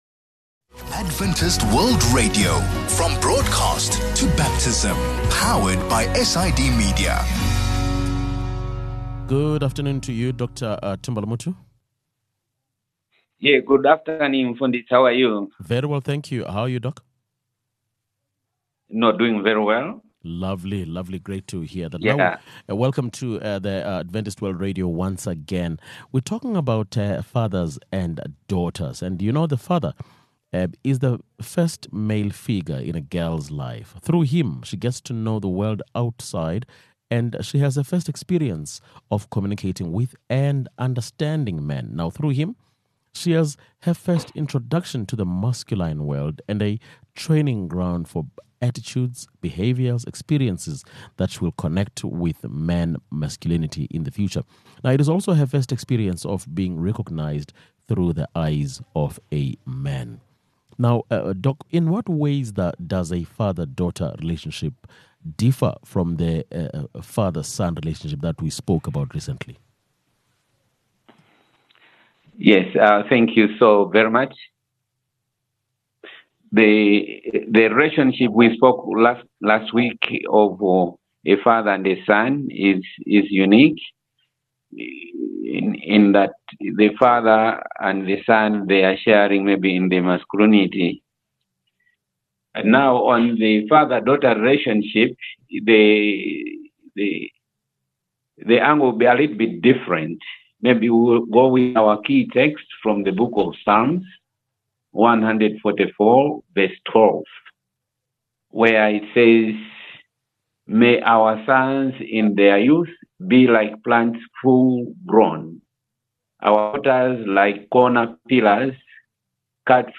A conversation about the dynamics of the father-daughter relationship.